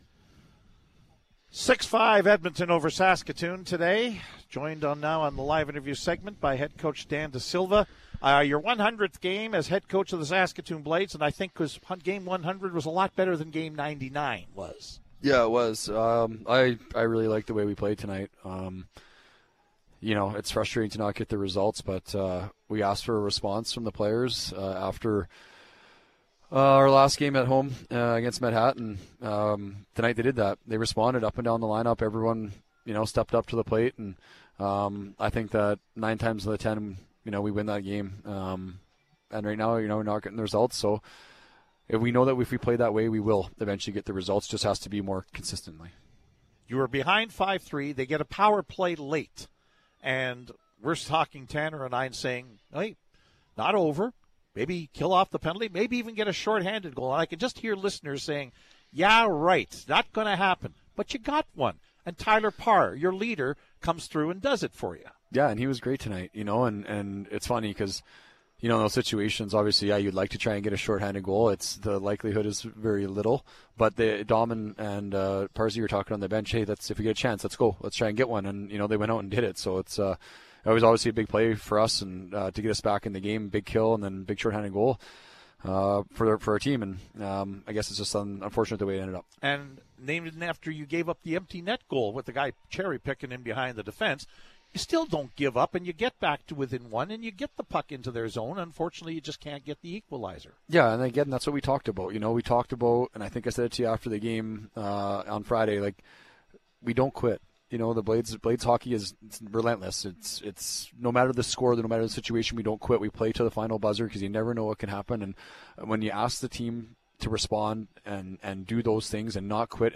Post-Game Show interview